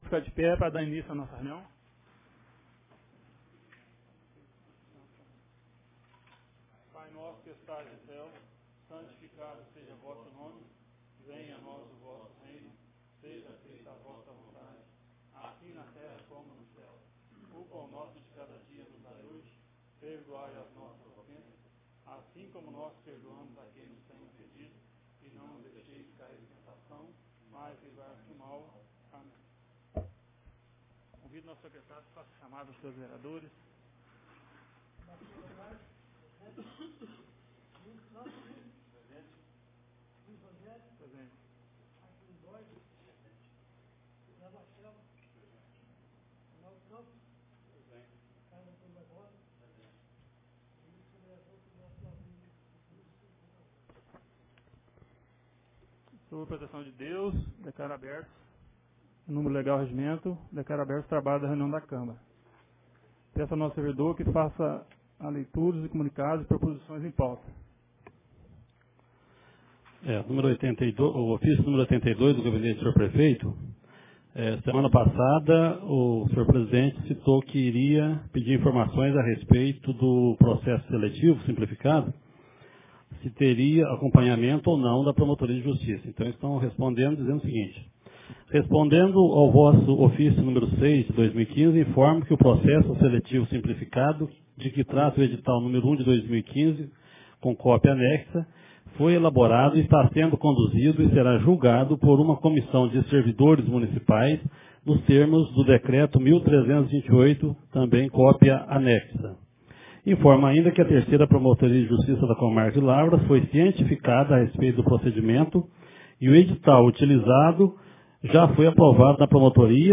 Ata da 2ª Reunião Ordinária de 2015